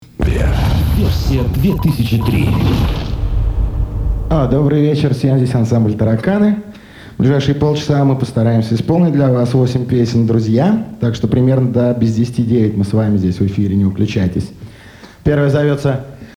В интернете эти записи моно и качество 128, здесь стерео и 320.
Разговор в студии